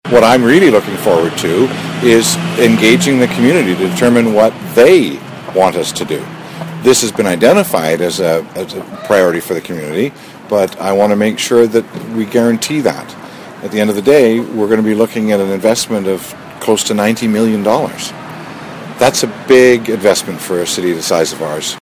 Mayor Bill McKay says he’s looking forward to the public consultation process….